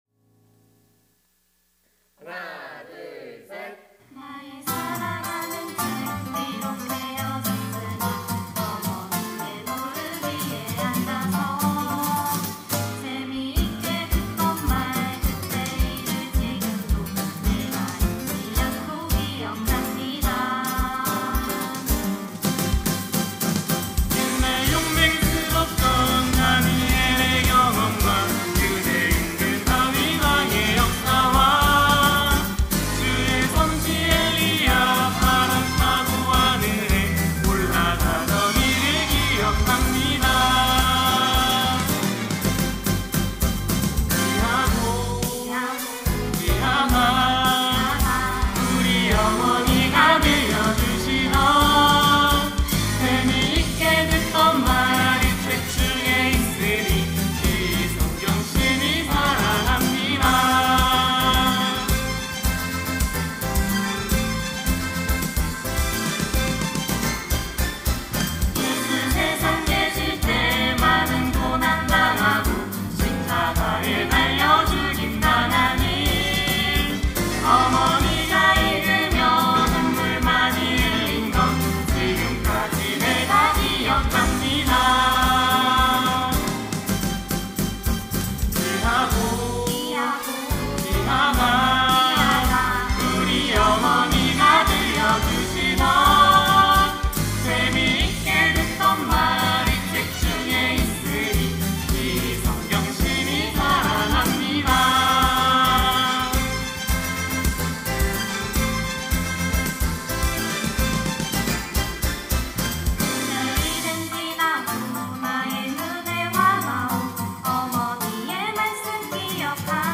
특송과 특주 - 나의 사랑하는 책
이름 청년부 교육팀